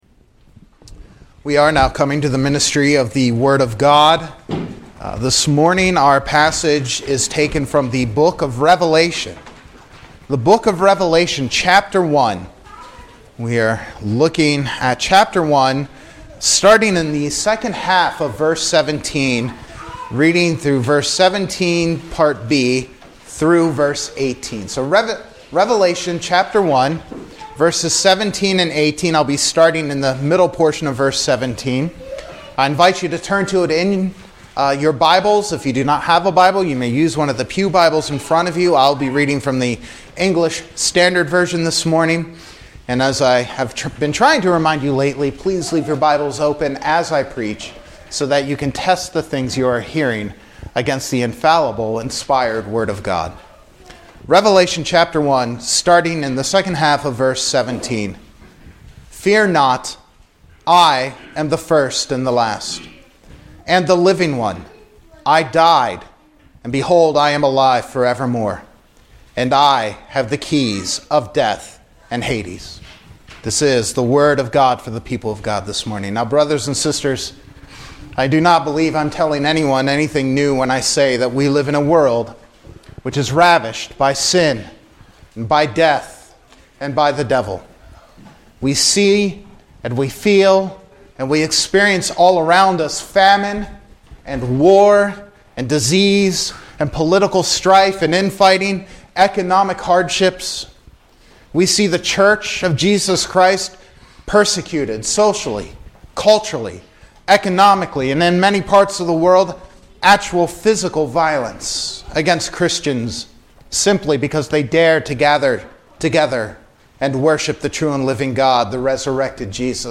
Easter sermon 2022 (Revelation 1:17-18)